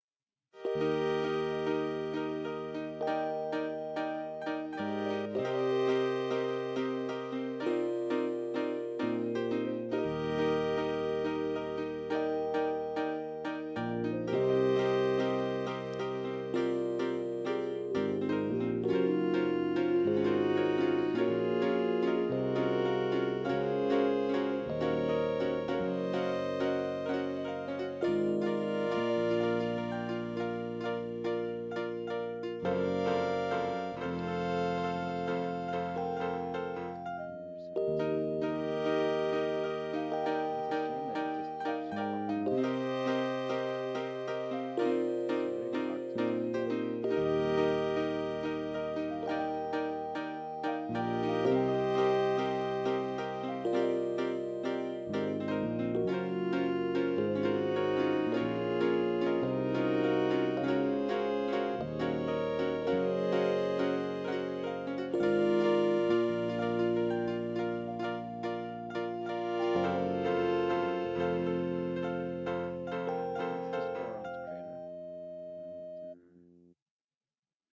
Simple Chords melody